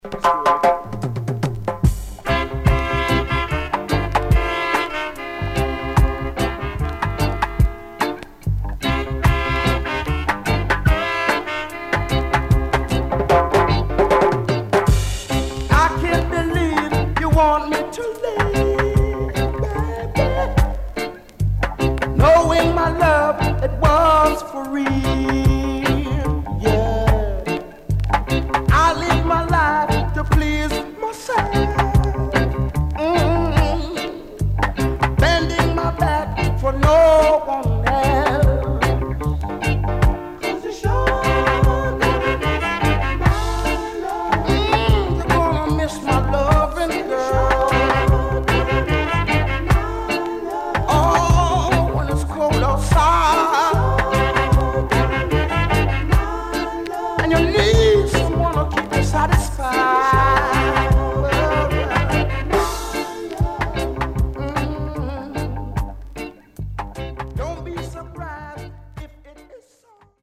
【12inch】
SIDE A:盤質は良好です。